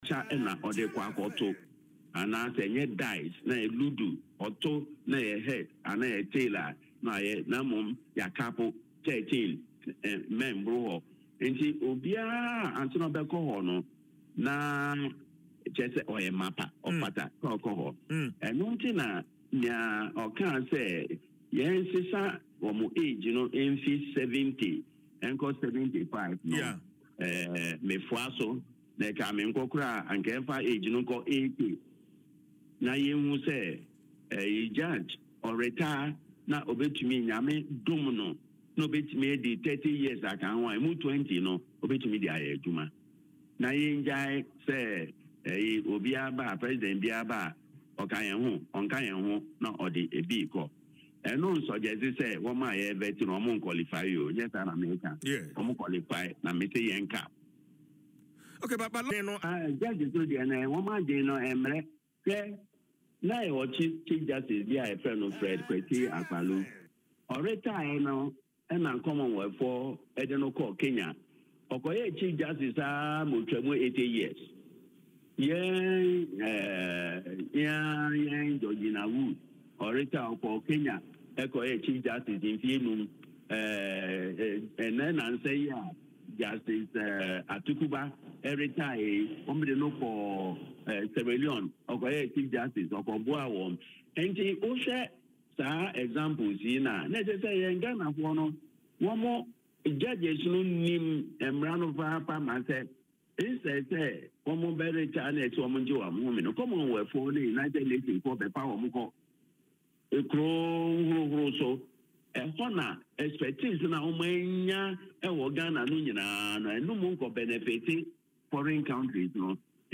Speaking in an interview on Adom FM’s Dwaso Nsem